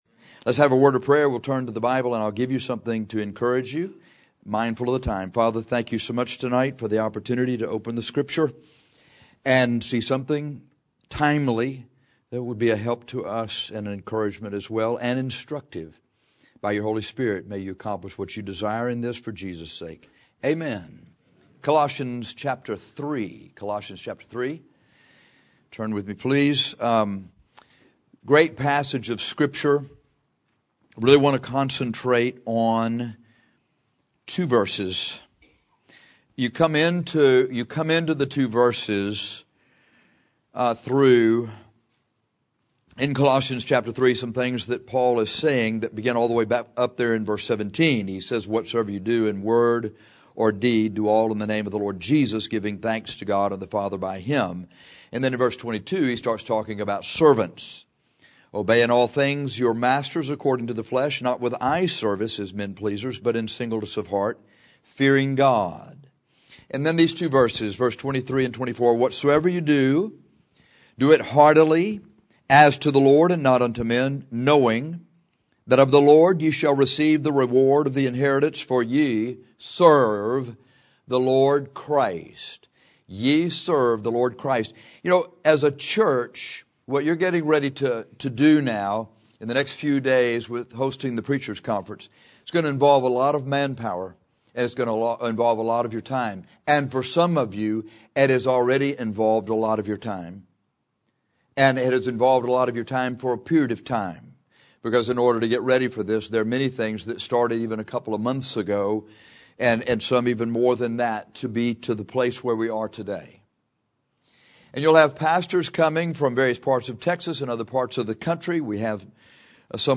We will organize our thoughts for this sermon by asking four questions.